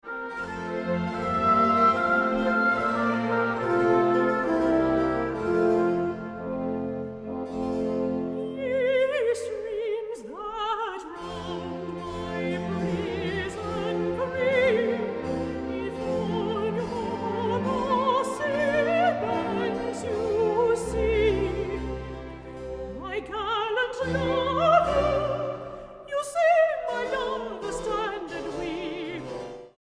Vocal treasures of the 18th & 19th centuries
Soprano